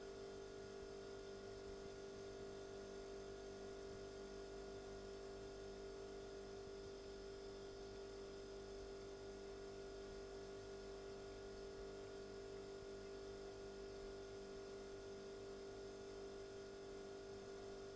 冰箱压缩机（循环）。
描述：从冰箱压缩机上记录无人机 包括后台时钟